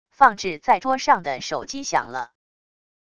放置在桌上的手机响了wav音频